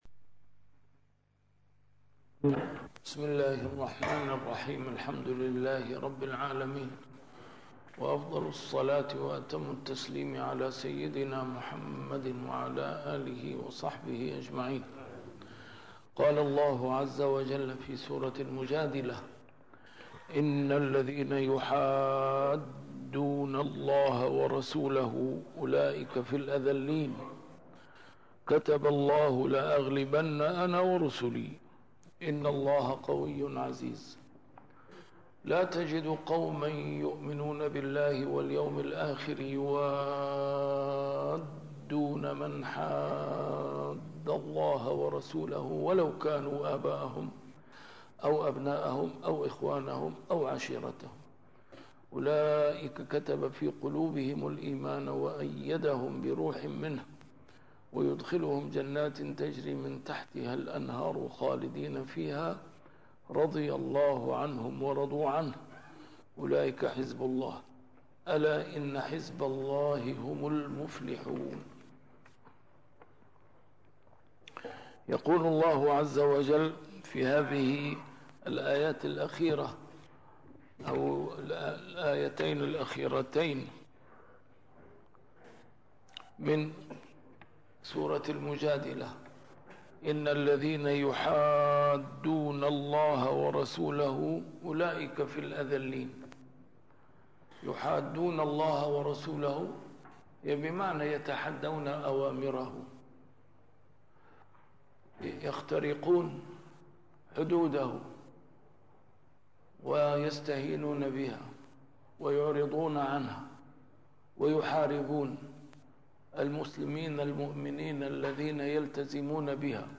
A MARTYR SCHOLAR: IMAM MUHAMMAD SAEED RAMADAN AL-BOUTI - الدروس العلمية - تفسير القرآن الكريم - تسجيل قديم - الدرس 765: المجادلة 20-22